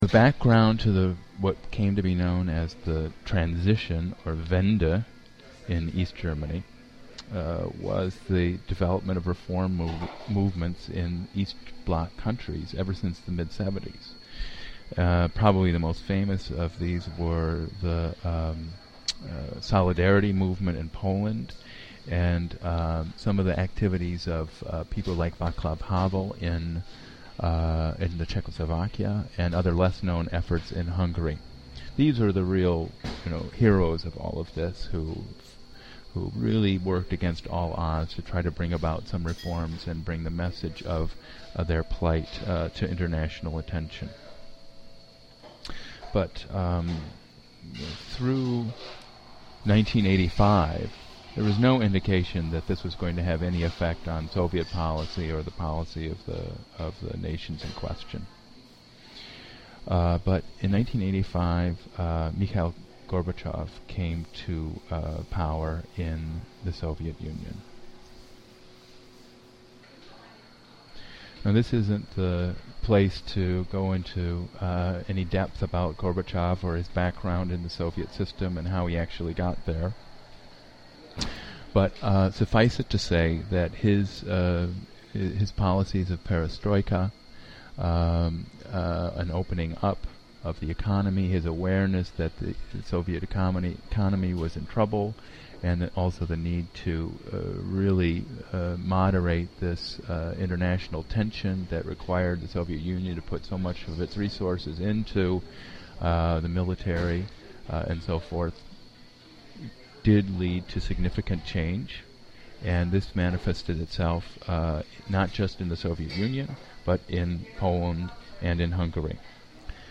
LECTURE 10 A Die Wende (The Transition)